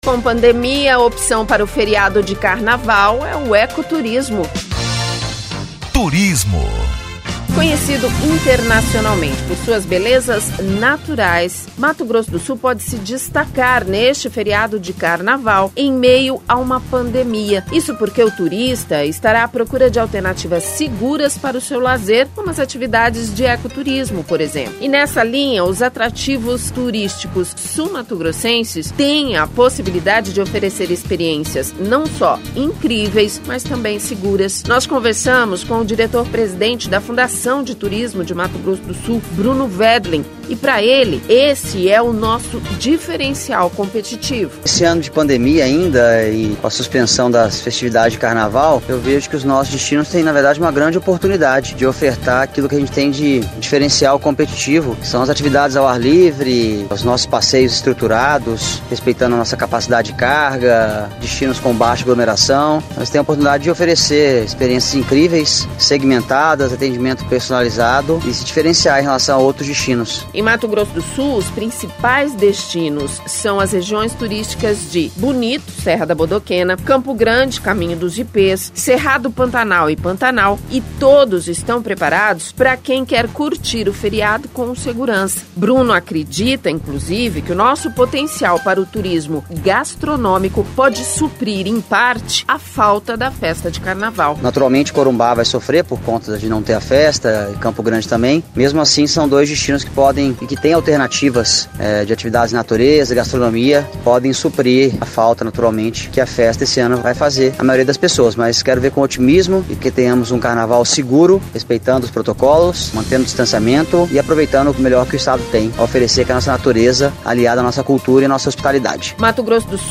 Nós conversamos com o diretor-presidente da Fundação de Turismo de Mato Grosso do Sul, Bruno Wendling, e pra ele esse é o nosso diferencial competitivo.